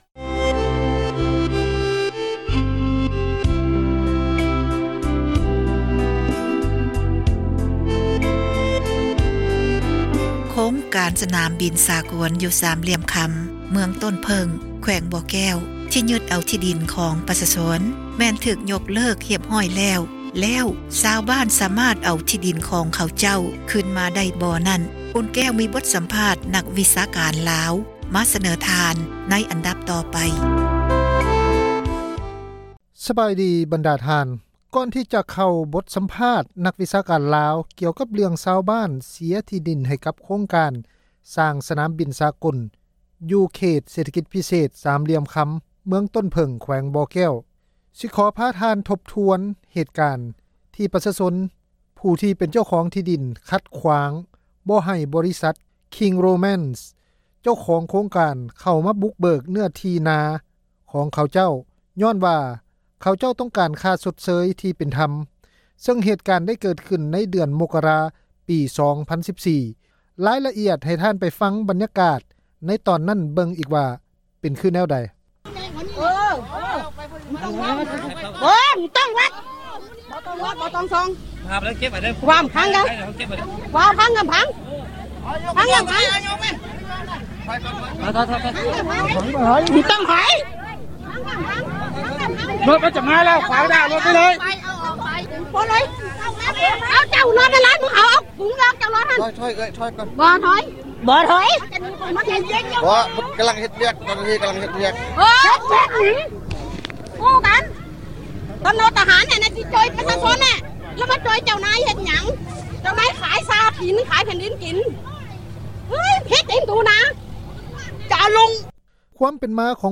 ບົດສຳພາດ ນັກວິຊາການລາວ ກ່ຽວກັບເລື້ອງຊາວບ້ານ ເສັຍທີ່ດິນ ໃຫ້ກັບໂຄງການ ສ້າງສະໜາມບິນສາກົນ ຢູ່ເຂດເສຖກິດພິເສດ ສາມຫລ່ຽມຄຳ ຢູ່ເມືອງຕົ້ນເຜິ້ງ ແຂວງບໍ່ແກ້ວ.